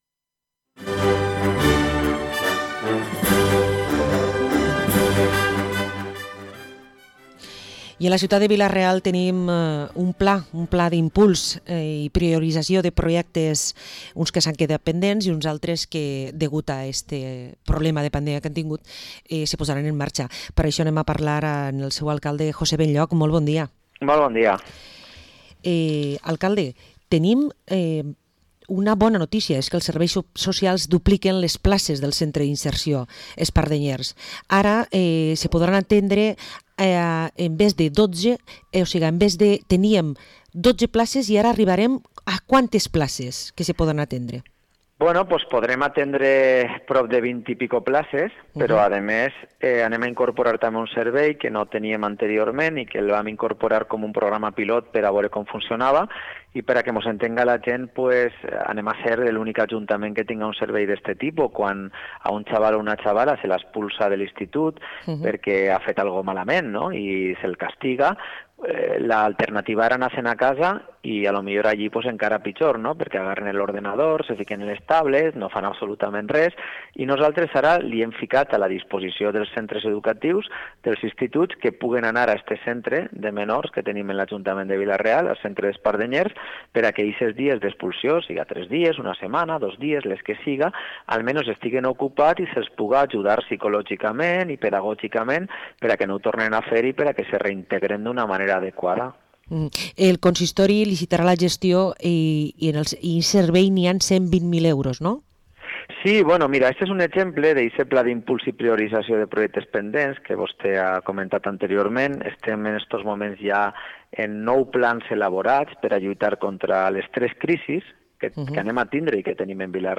Entrevista a José Benlloch, alcalde de Vila-real